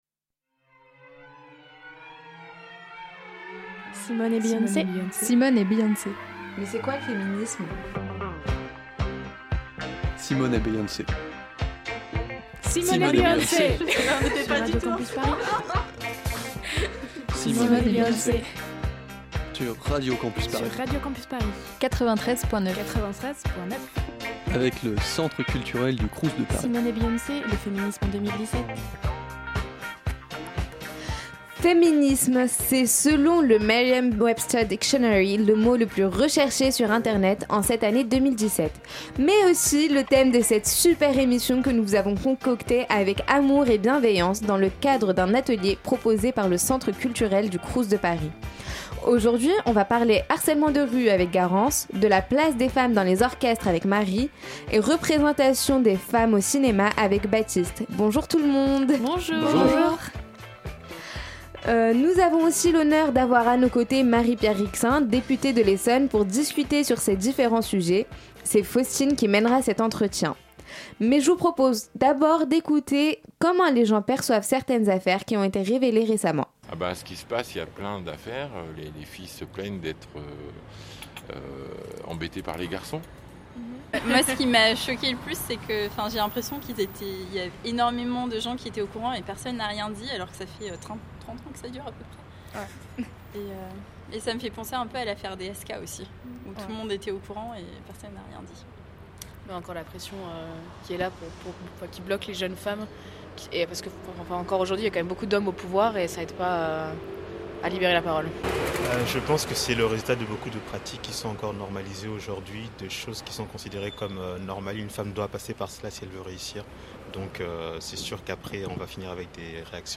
Un reportage sur la place des femmes dans les orchestres, réalisé lors d'une répétition du Choeur et Orchestre de la Sorbonne (COSU) avec